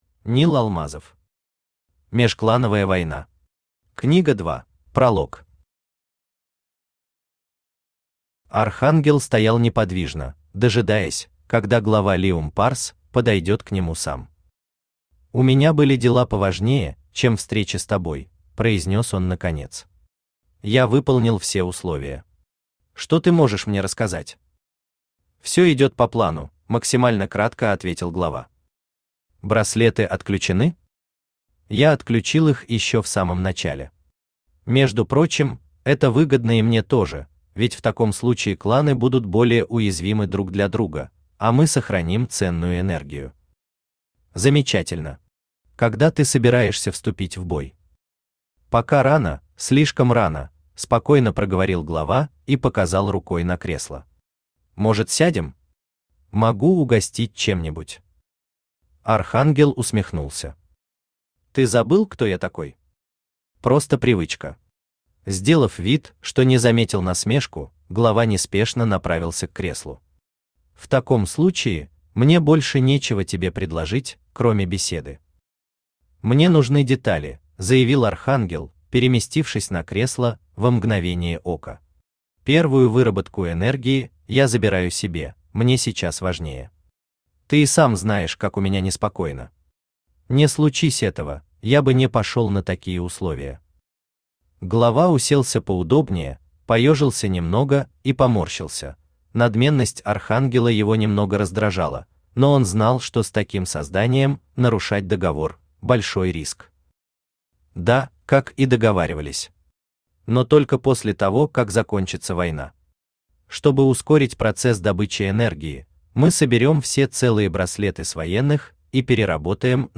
Аудиокнига Межклановая война. Книга 2 | Библиотека аудиокниг
Книга 2 Автор Нил Алмазов Читает аудиокнигу Авточтец ЛитРес.